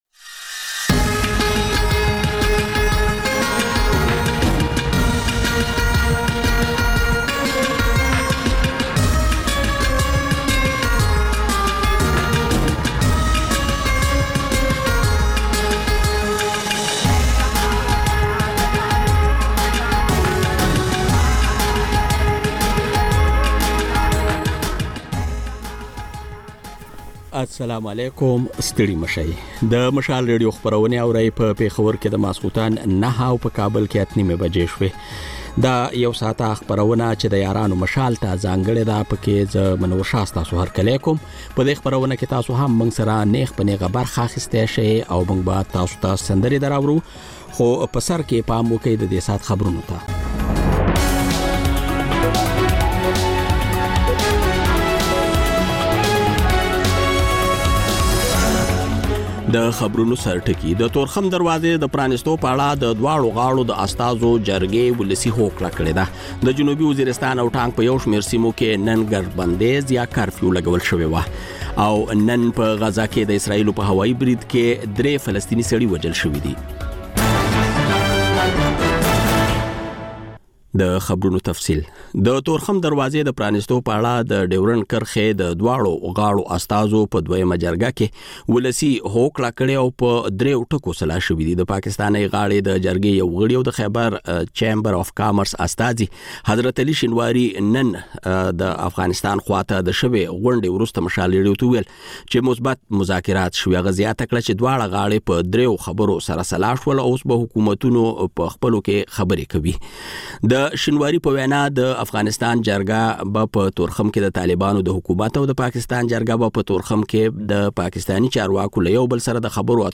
د یارانو مشال په ژوندۍ خپرونه کې له اورېدونکو سره بنډار لرو او سندرې خپروو. دا یو ساعته خپرونه هره ورځ د پېښور پر وخت د ماخوستن له نهو او د کابل پر اته نیمو بجو خپرېږي.